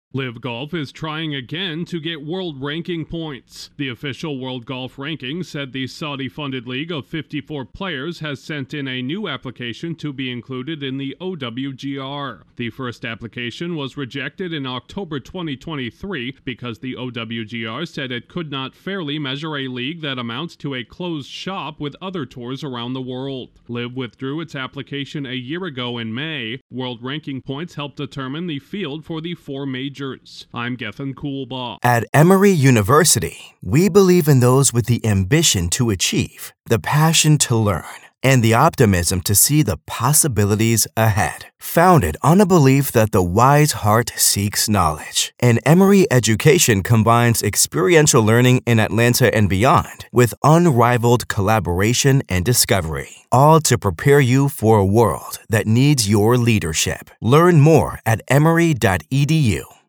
Golf’s most controversial tour is taking another shot at the world rankings. Correspondent